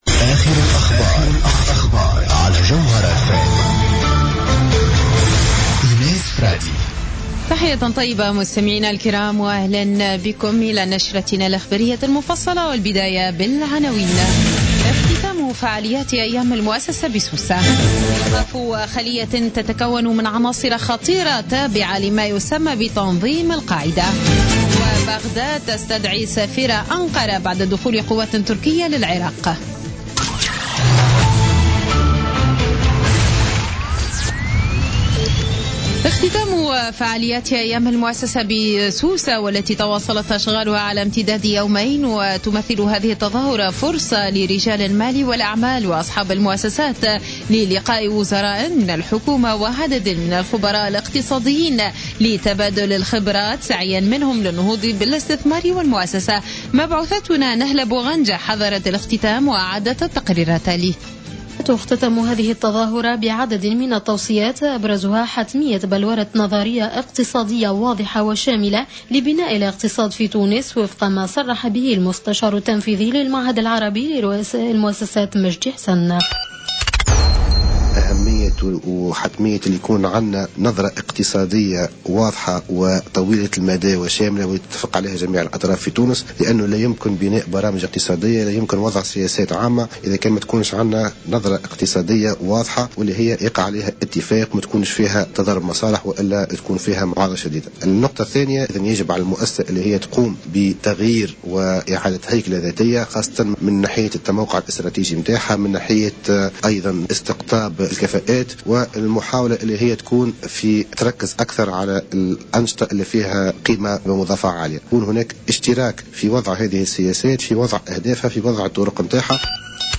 Journal Info 19:00 du samedi 05 Décembre 2015